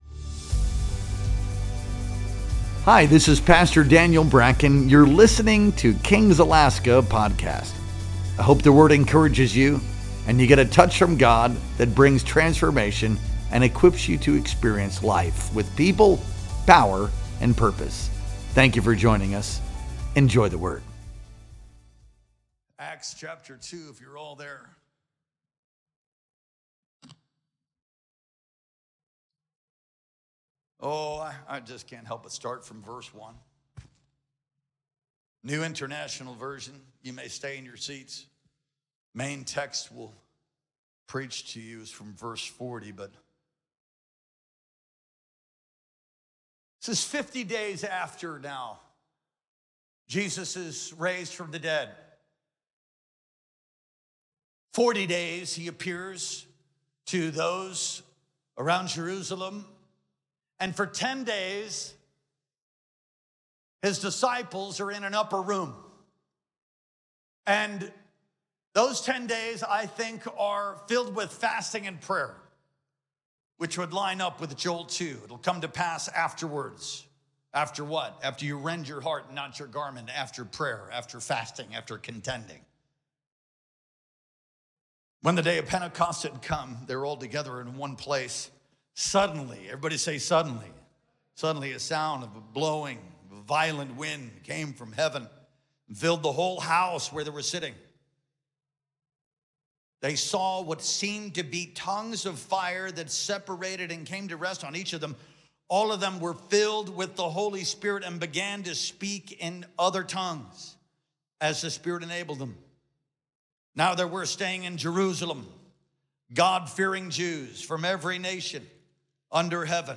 Our Sunday Worship Experience streamed live on June 8th, 2025.